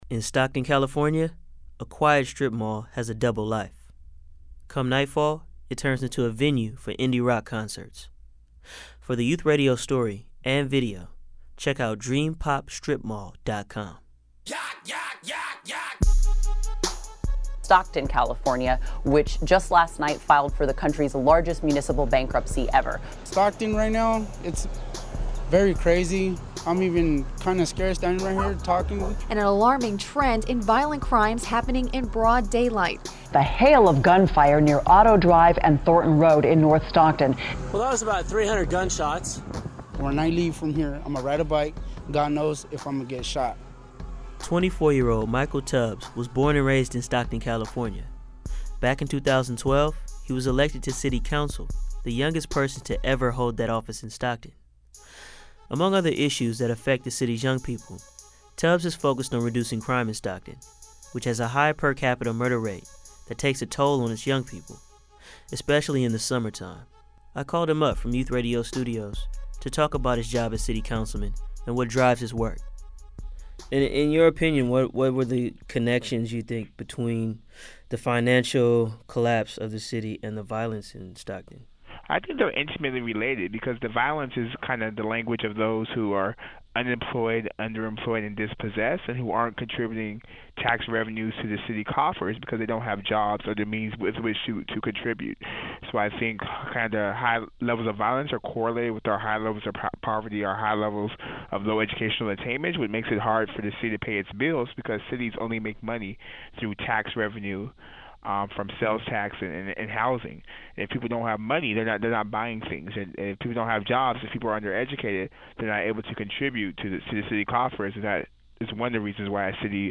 Michael Tubbs Speaking
Tubbs talked to Youth Radio about his work.